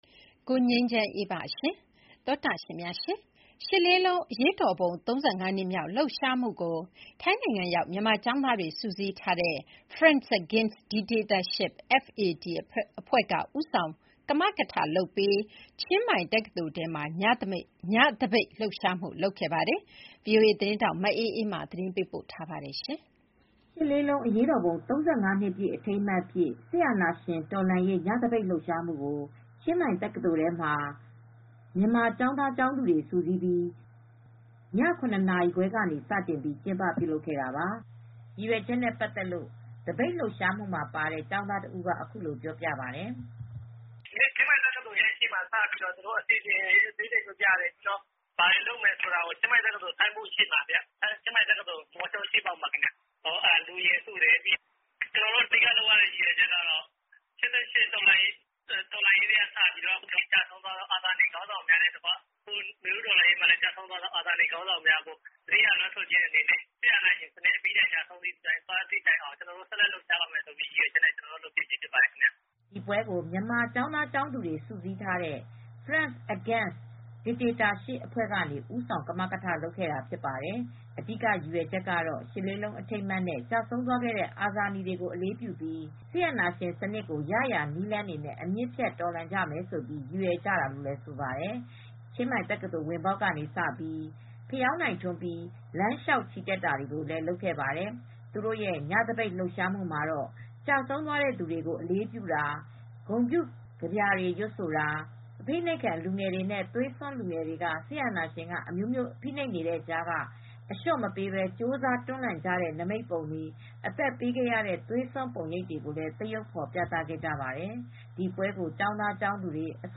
ရည်ရွယ်ချက်နဲ့ပတ်သက်လို့ သပိတ်လှုပ်ရှားမှုမှာပါတဲ့ ကျောင်းသားတဦးကအခုလိုပြောပါတယ်။